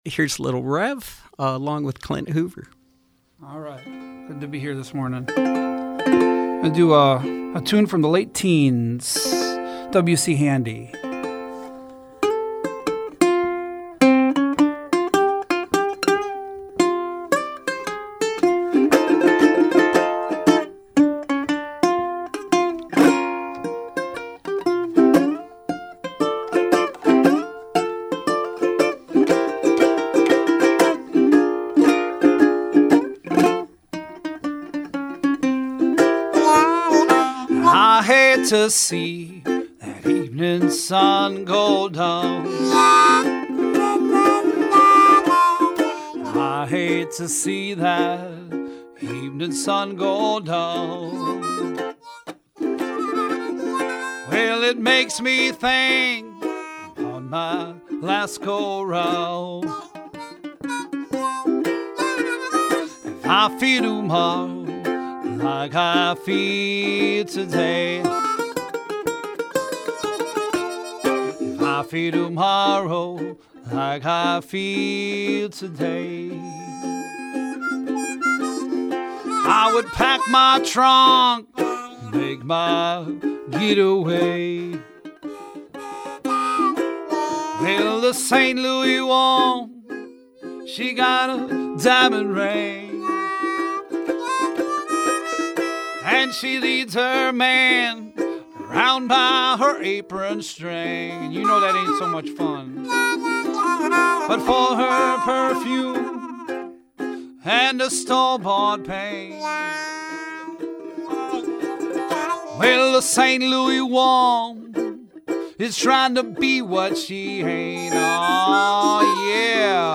ukulele and mandolin